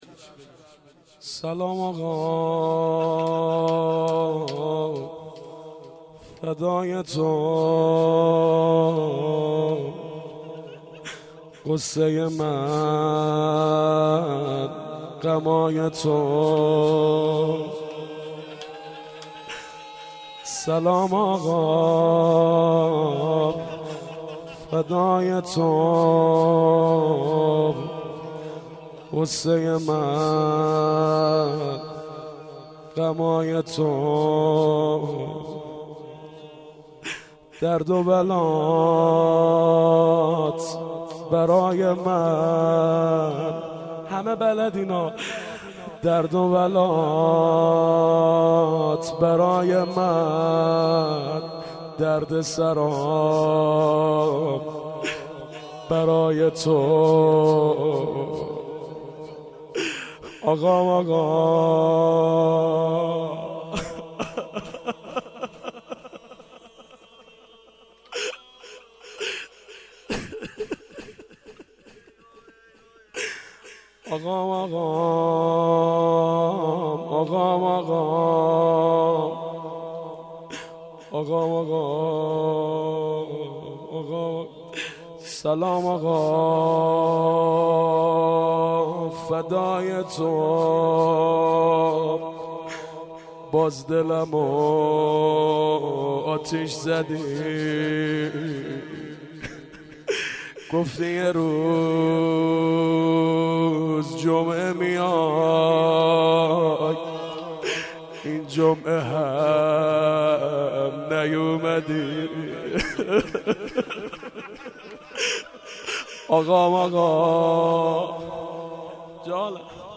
شب اول محرم
مداحی